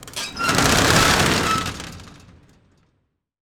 SHATTER 2 -S.WAV